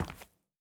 Linoleum_Mono_03.wav